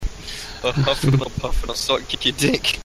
Play, download and share Huff & Puff original sound button!!!!
huff-and-puff.mp3